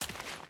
Dirt Walk 1.ogg